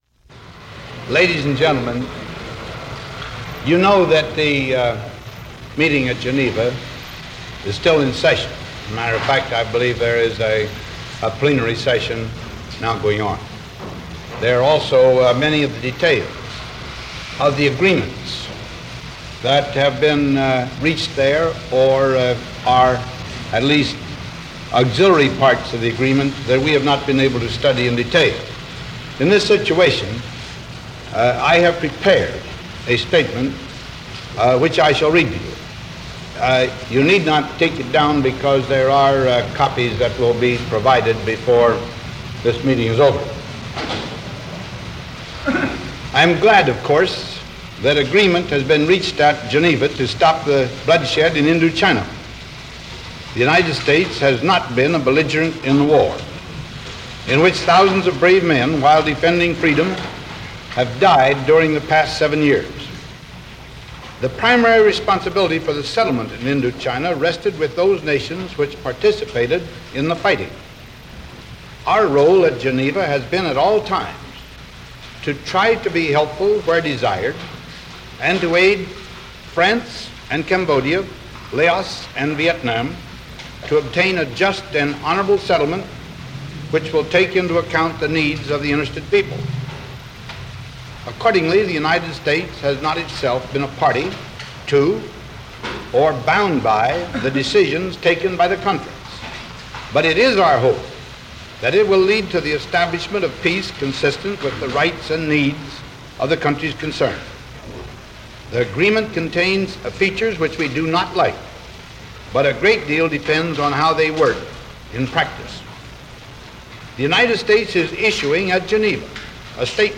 President Eisenhower (reading prepared statement): Ladies and gentlemen, you know that the meeting at Geneva is still in session; as a matter of fact, I believe there is a plenary session now going on.